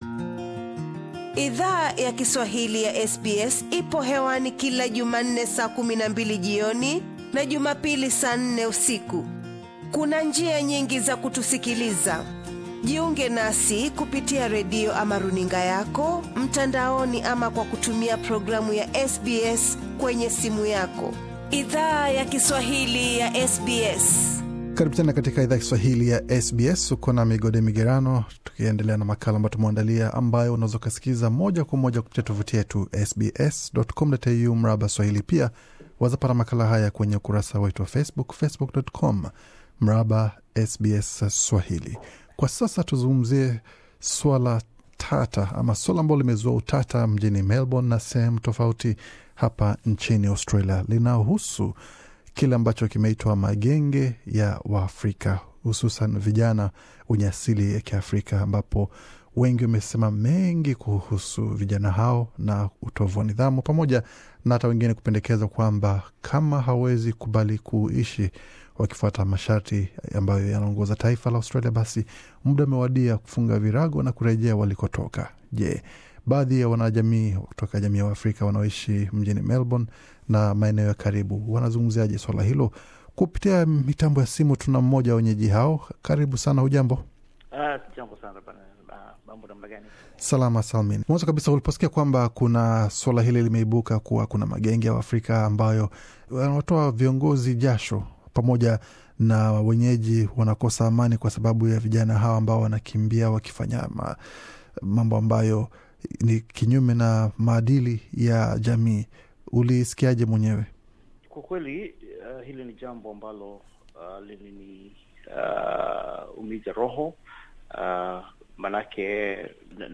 SBS Swahili imezungumza na kiongozi mmoja kutoka jammy ya Waafrika wanaoishi Melbourne, kuhusu madai ya waziri wa mambo ya ndani Peter Dutton kuwa, wakazi wa Melbourne wanaogopa kutoka usiku kwa sababu ya magenge ya vijana wenye asili ya Afrika. Kiongozi huyo amefunguka kuhusu madhara ya madai ya waziri huyo kwa jamii pana ya Waafrika.